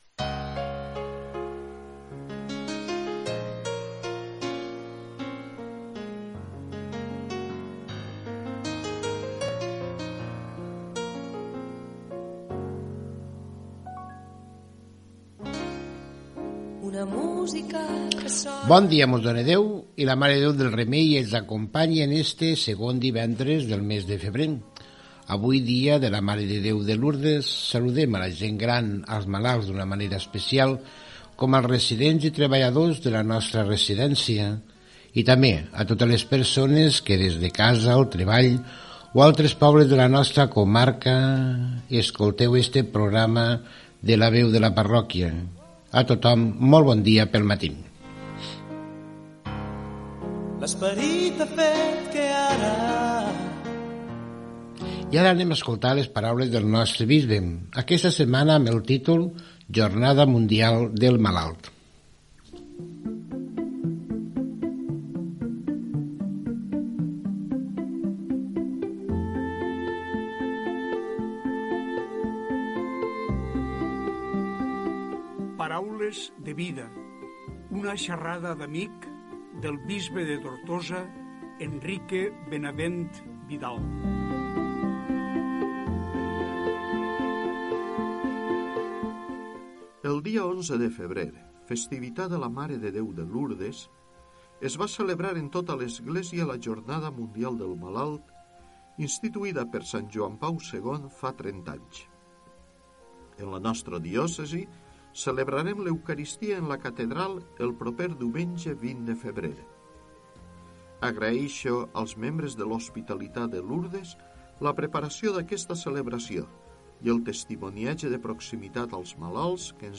b701d06f1466d290f470cf4c58fe5d3647baf2b3.mp3 Títol Ràdio Flix Emissora Ràdio Flix Titularitat Pública municipal Nom programa La veu de la parròquia Descripció Salutació, "Paraules de vida" del bisbe de Tortosa, Enrique Benavent sobre la Jornada Mundial del Malalt, Evangeli de Sant Lluc i homilia.